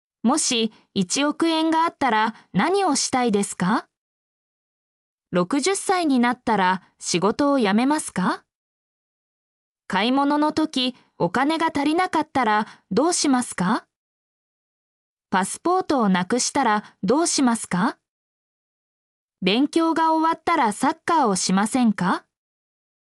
mp3-output-ttsfreedotcom-78_YoI0LnVY.mp3